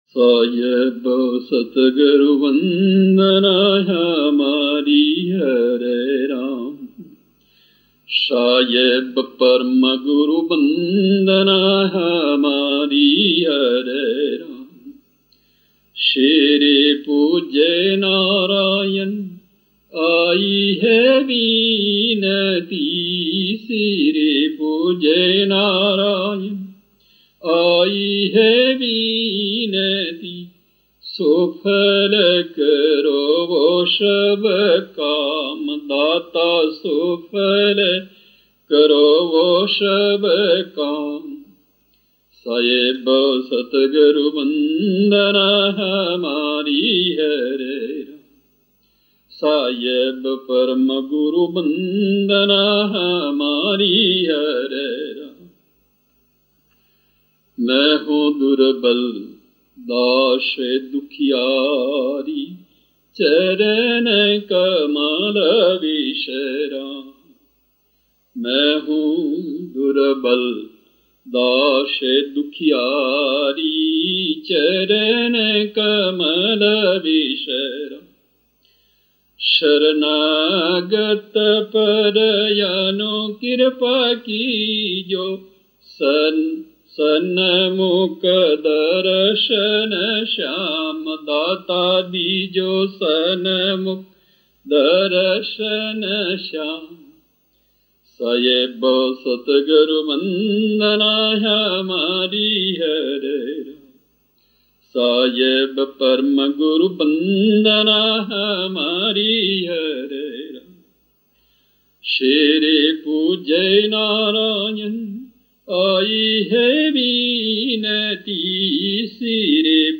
poslušajte bhađan